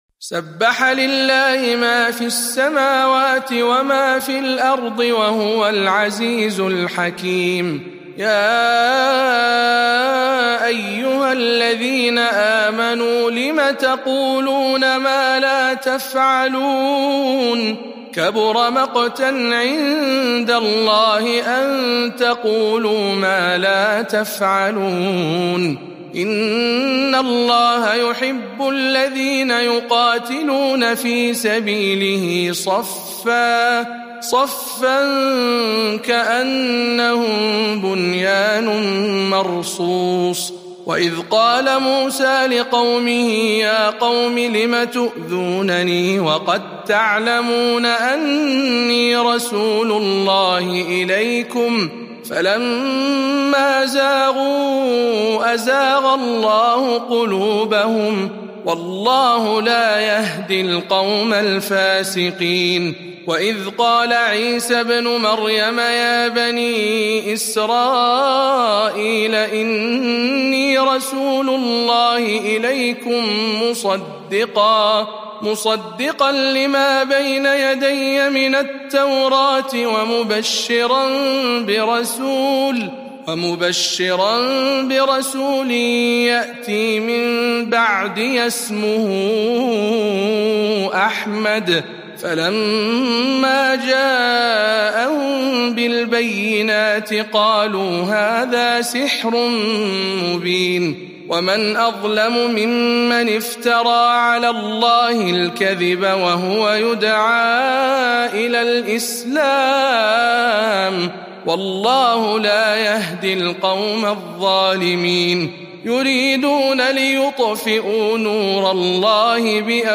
سورة الصف برواية شعبة عن عاصم